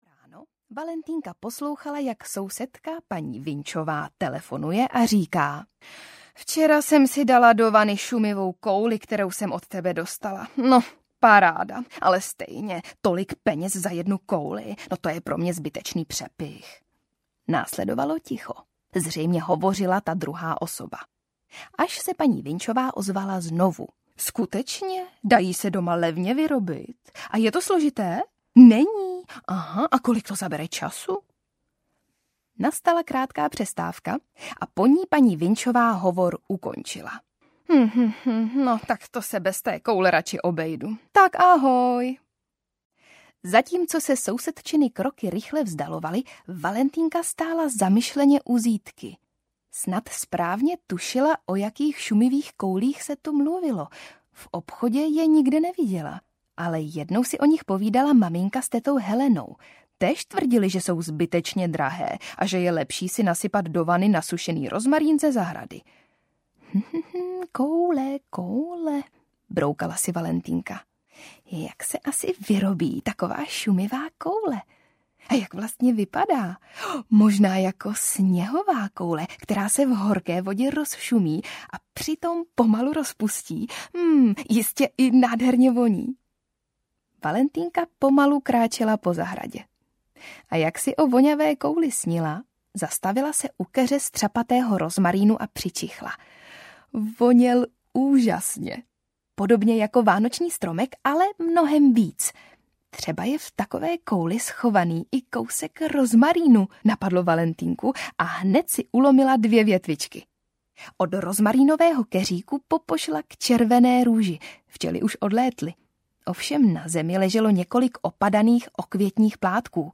Valentýnka a narozeniny audiokniha
Ukázka z knihy
• InterpretVeronika Khek Kubařová